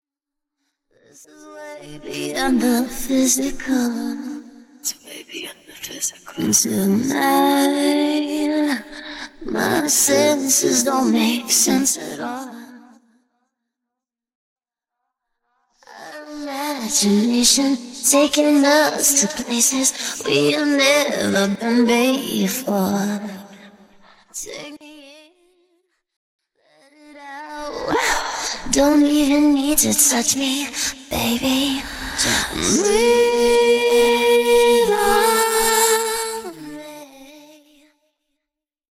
another great clean acapella, thanks again guys 🙂